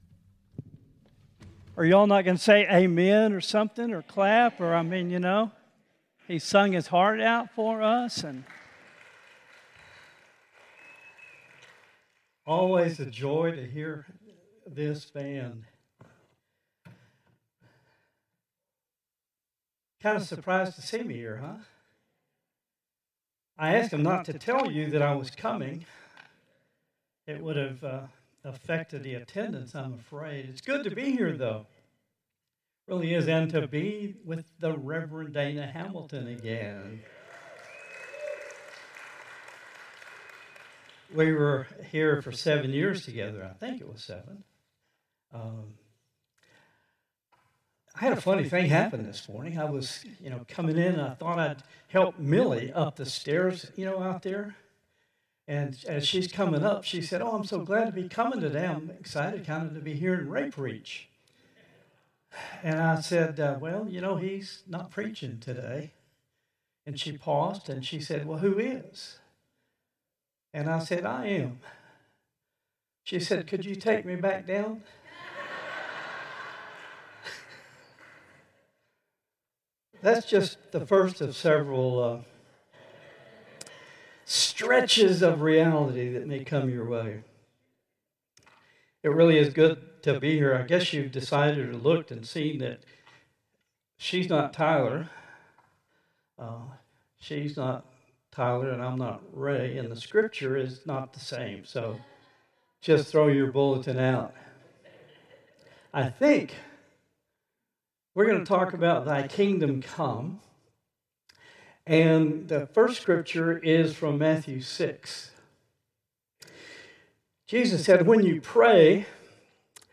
Contemporary Service 7/13/2025